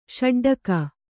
♪ ṣaḍḍaka